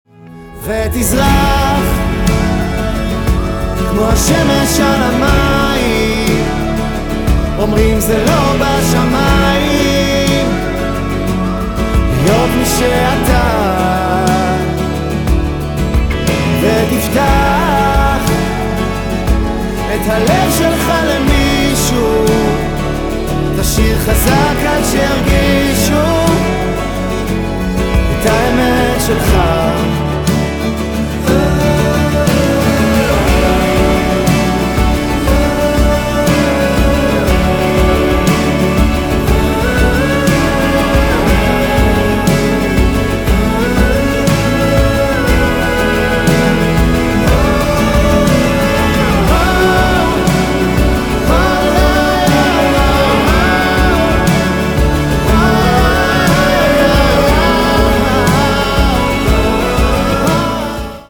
היי חברים, אני מחפש סאמפלים של קיק וסנר שנשמעים כבר בבסיס שלהם כמו בקטע המצורף – סאונד שמן, מלוטש ו"גמור".